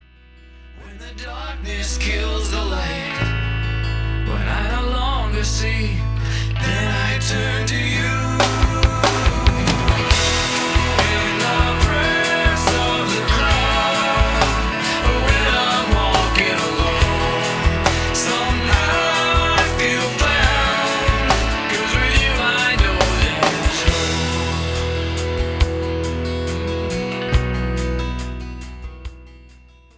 • CJM (Contemporary Jewish Music)